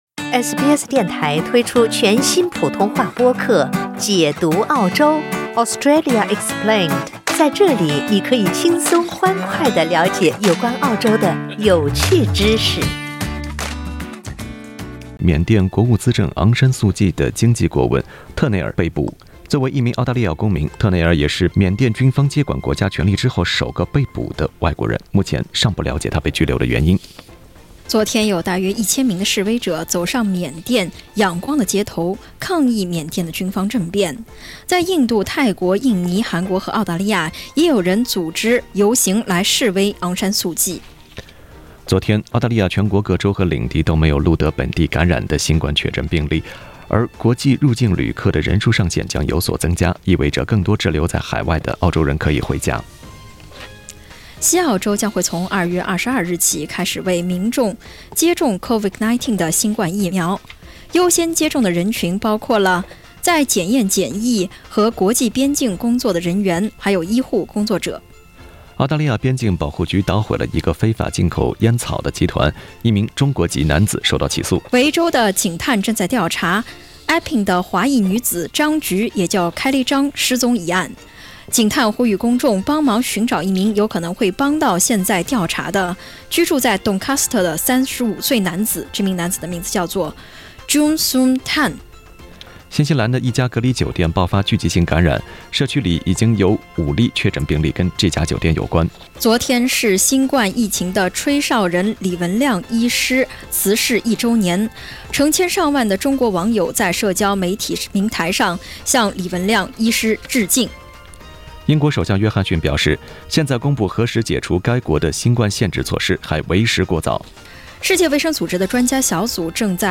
SBS早新聞（2月7日）